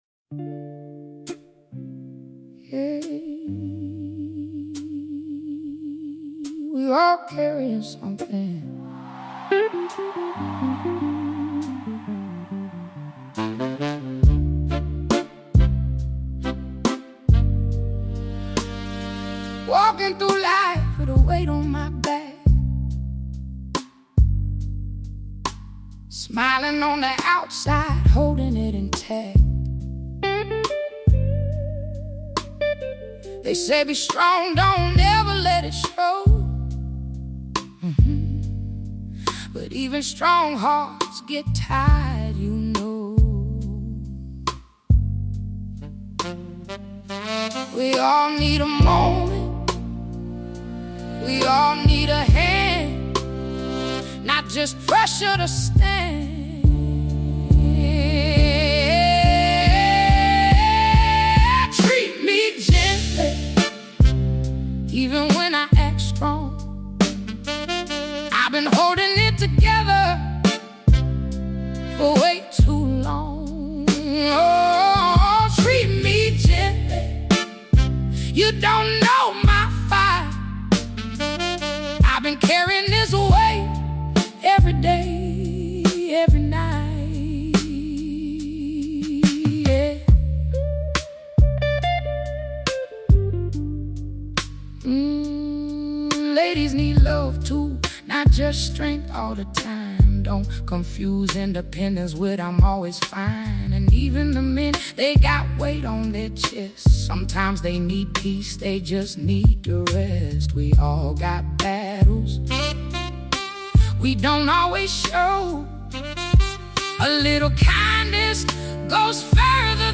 As you keep listening, the tone becomes more intimate.